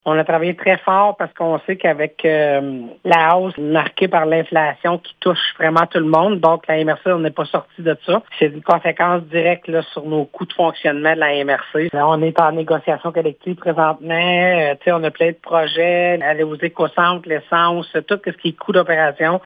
Entre 2023 et 2024, le budget de la MRC est passé de presque 13,5 millions $ à plus de 14,5 millions $. La préfète de la Vallée-de-la-Gatineau, Chantal Lamarche, explique que cet écart est principalement causé par l’inflation :